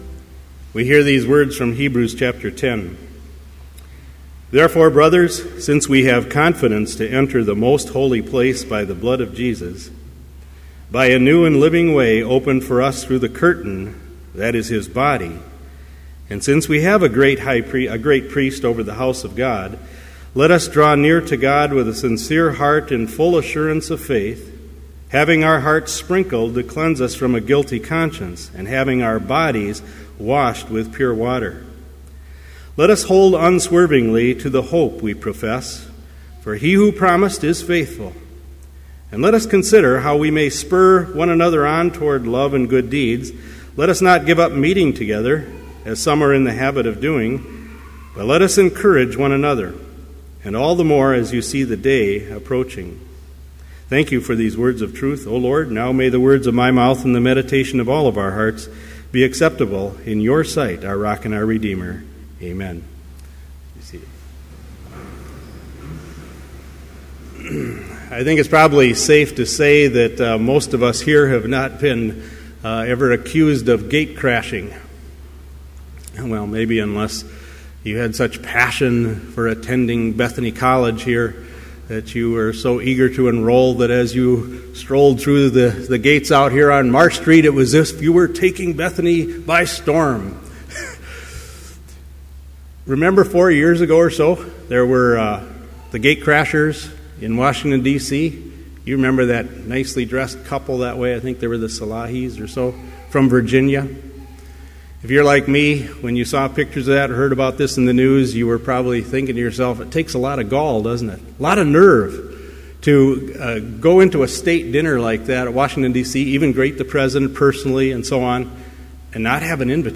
Complete Service
• Prelude
• Homily
This Chapel Service was held in Trinity Chapel at Bethany Lutheran College on Tuesday, October 22, 2013, at 10 a.m. Page and hymn numbers are from the Evangelical Lutheran Hymnary.